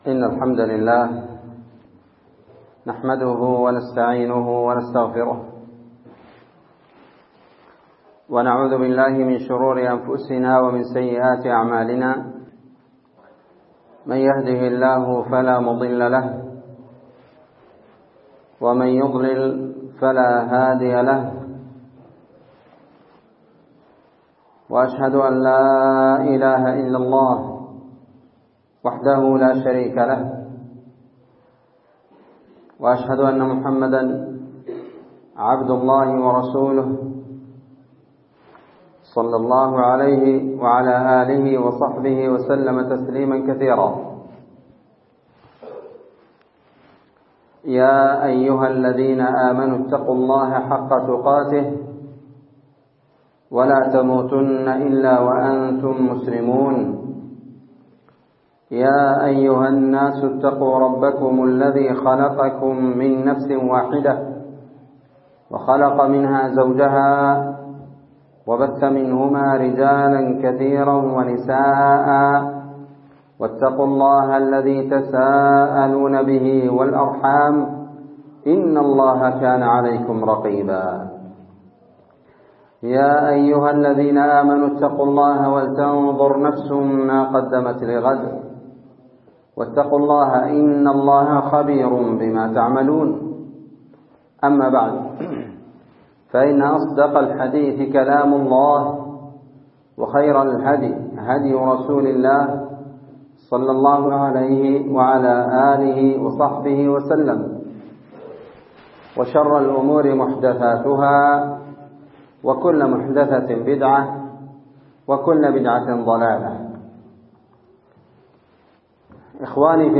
محاضرة بعنوان نصيحة مهمة حول القرآن والعناية به وأقسام الناس في هجره